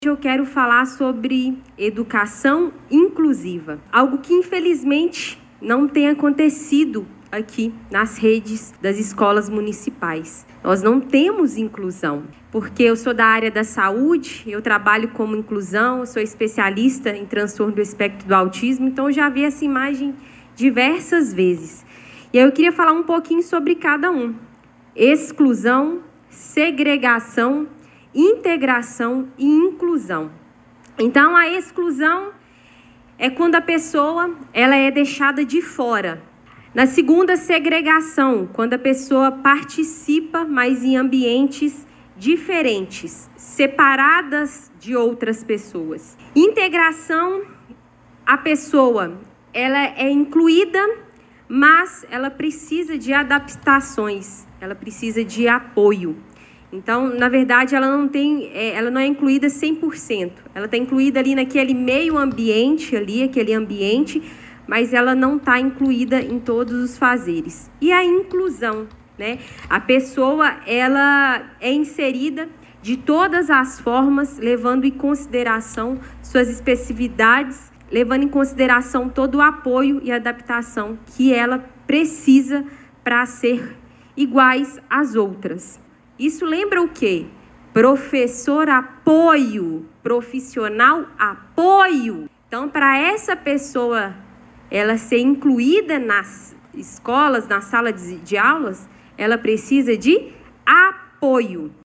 A reunião ordinária da Câmara Municipal de Pará de Minas, realizada ontem, 24 de março, foi marcada por debates e um forte desabafo com denúncias graves sobre a situação da educação inclusiva na cidade. A vereadora Camila Araújo (PSDB), conhecida como Camila Mão Amiga, utilizou a tribuna para expor a falta de suporte adequado a estudantes que possuem necessidades especiais, afirmando que o que ocorre hoje nas escolas municipais não é inclusão.